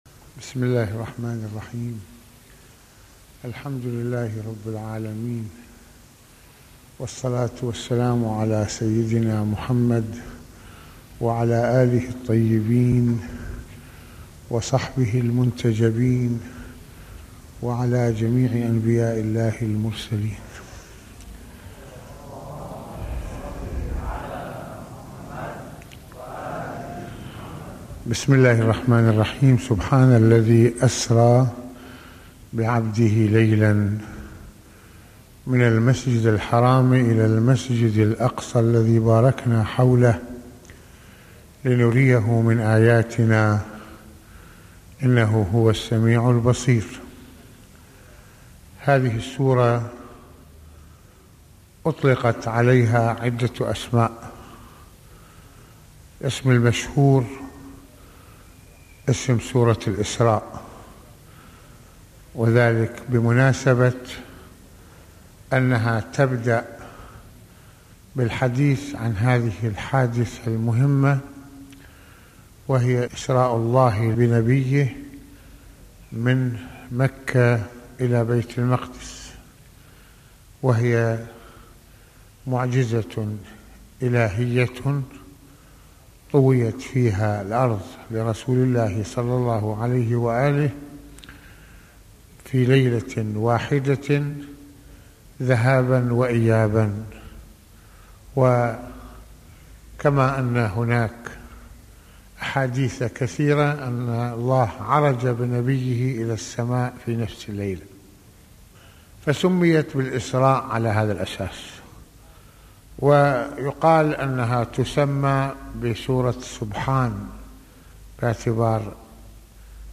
- في هذه المحاضرة يتعرض العلامة المرجع السيد فضل الله (رض) بالشرح والدلالة إلى ما جاء في سورة الإسراء المباركة من محطّات أساسية ومن ذلك إسراء النبي(ص) إلى بيت المقدس من مكّة المكرّمة ومن ثم عروجه إلى الملأ الأعلى...